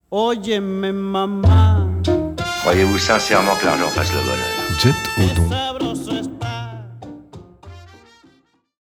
mp3_jinglodon1_ambianceFilm.mp3